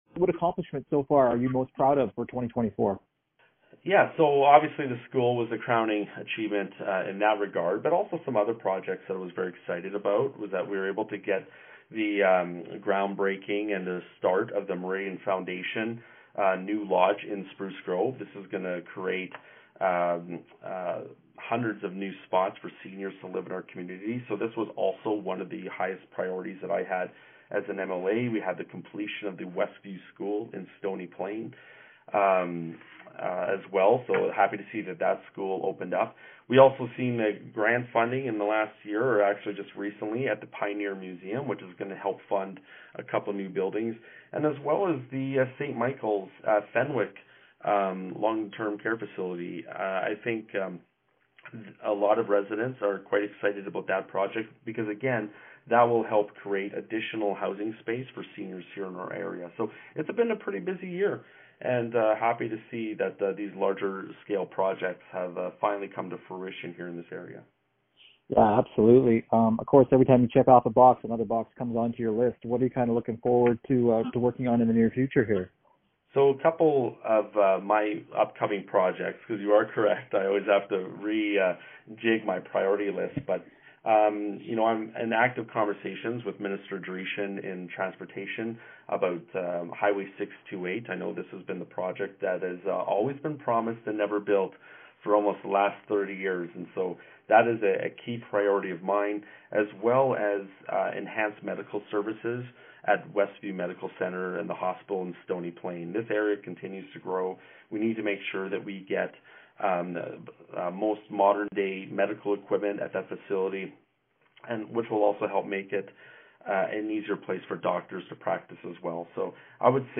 Searle Turton year end interview
AB Spruce Grove Stony Plain MLA Searle Turton year end interview.